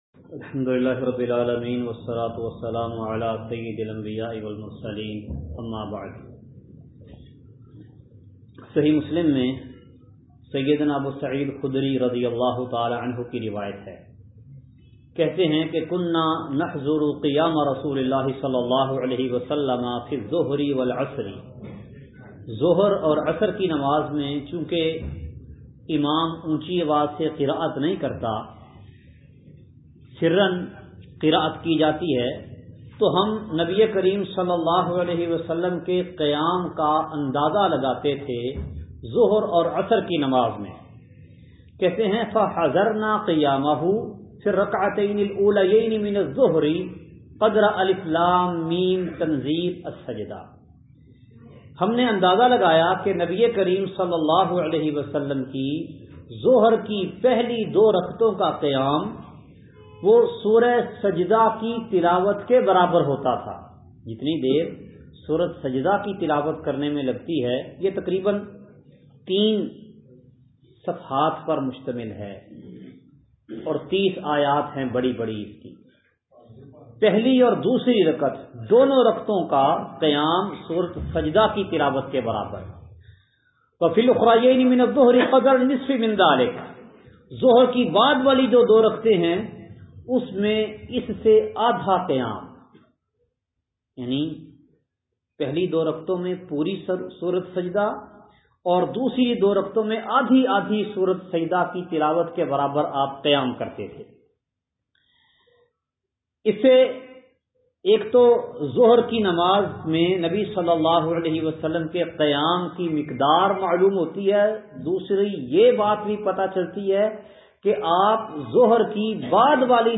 قيام میں قراءت کی مقدار درس کا خلاصہ پانچوں فرض نمازوں میں فاتحہ کے بعد قراءت کی مسنون مقدار آڈیو فائل ڈاؤنلوڈ کریں × الحمد لله رب العالمين، والصلاة والسلام على سيد الأنبياء والمرسلين، أما بعد!